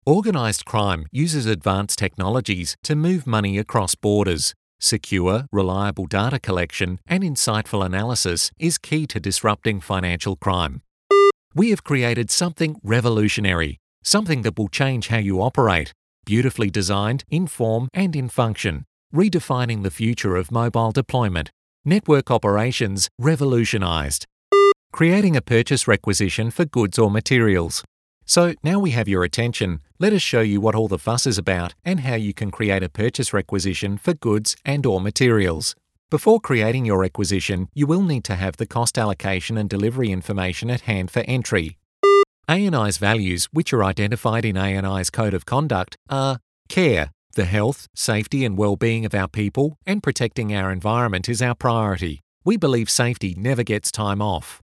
English (Australia)
Deep, Versatile, Warm, Corporate
Corporate